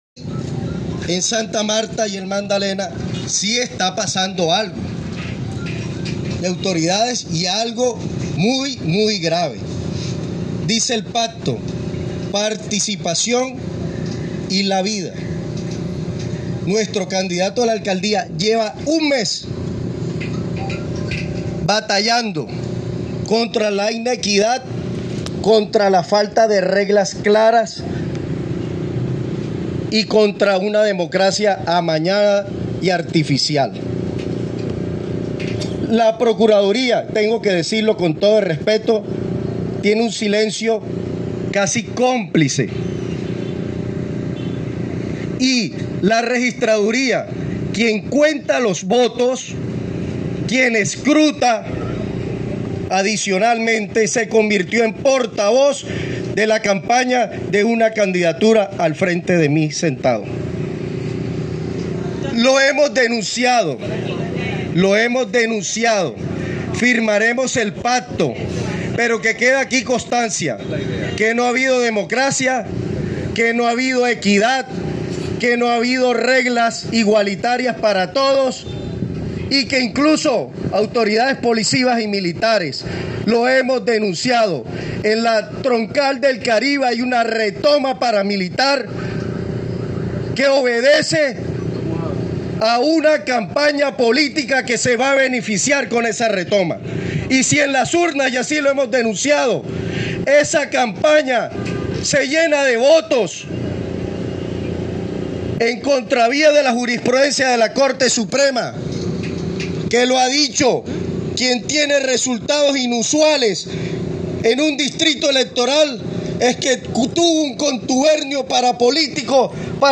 Las duras declaraciones se dieron durante la firma de un pacto por la sana participación electoral.